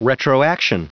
Prononciation du mot retroaction en anglais (fichier audio)
Prononciation du mot : retroaction